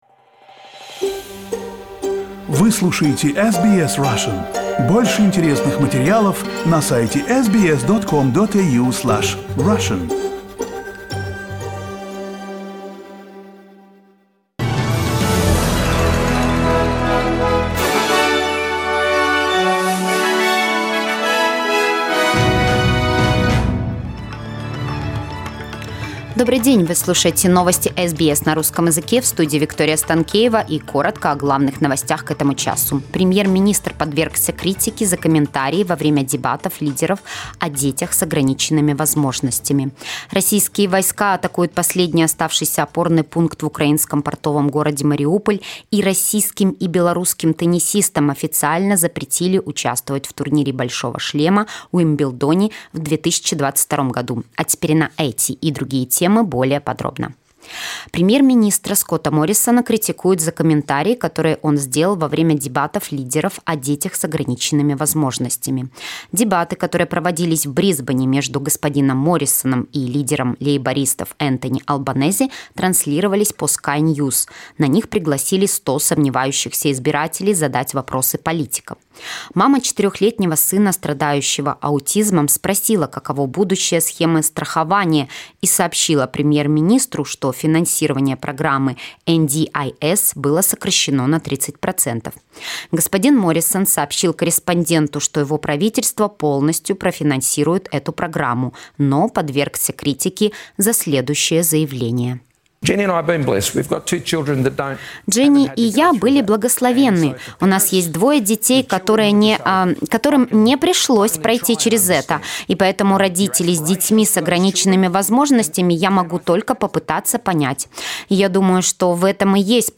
SBS news in Russian - 21.04